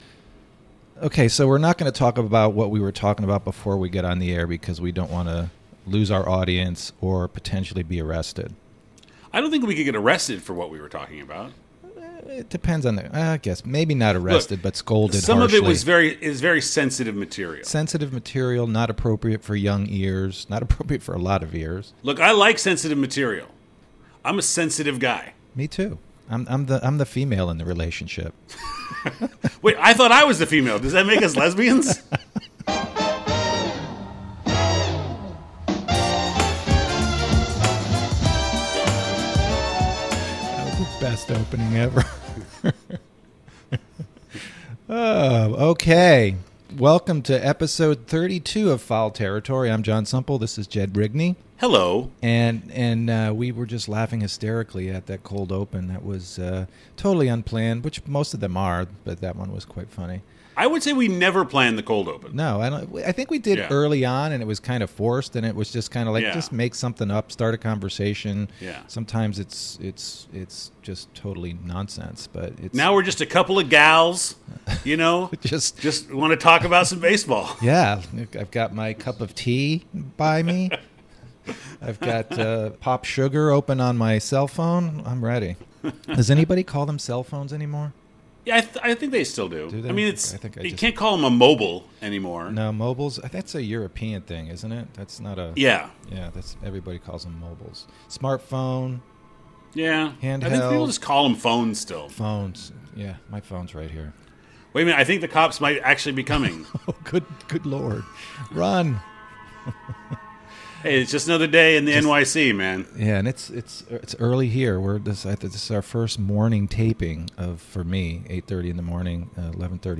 Episode 32 of Foul Territory: A Baseball Podcast was completed glitch-free!